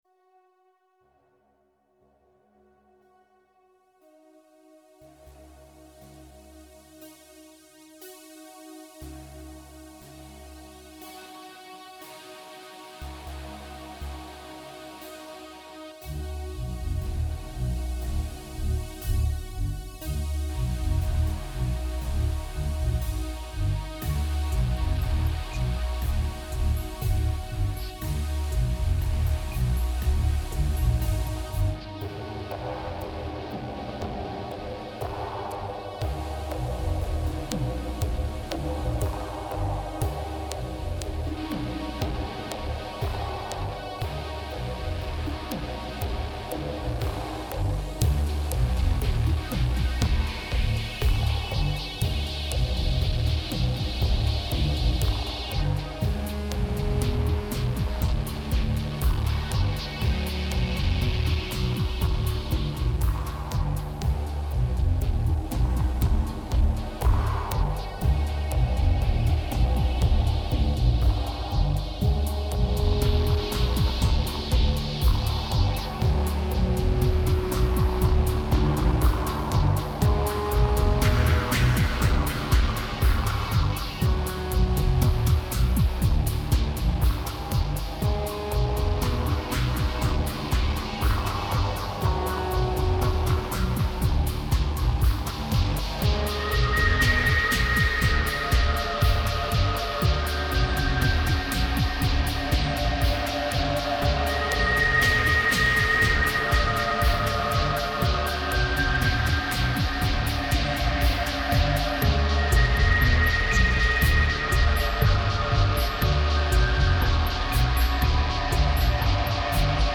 dirty reverb electro sounds
2280📈 - 1%🤔 - 120BPM🔊 - 2009-01-11📅 - -505🌟